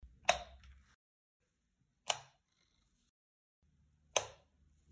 Вы найдете различные варианты щелчков и клацанья выключателя: от классических резких до современных мягких.
Выключатель в квартире несколько раз выключили и включили